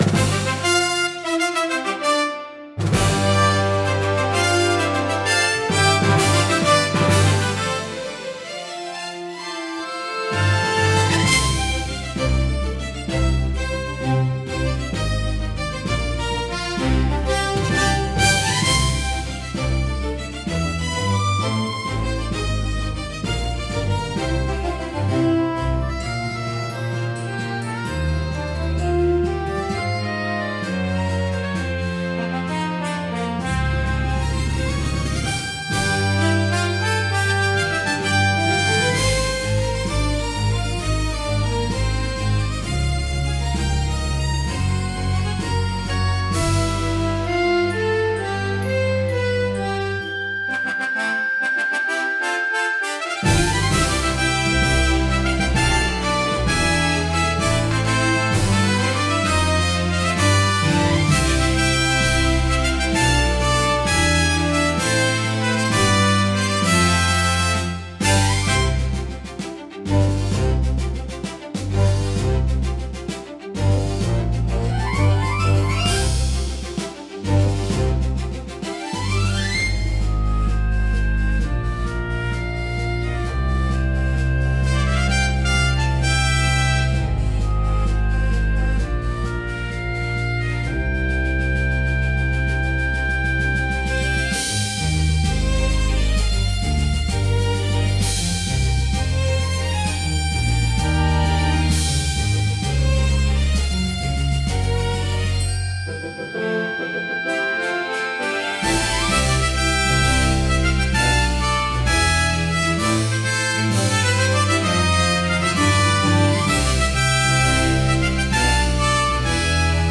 高揚感と喜びを強調し、場の雰囲気を盛り上げる役割を果たします。華やかで勢いのあるジャンルです。